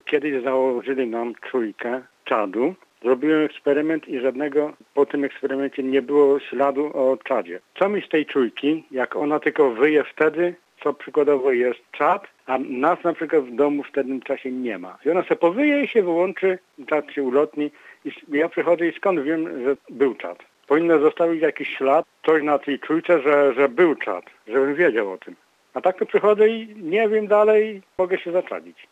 04.08-tel.-1-czujka-czadu.wav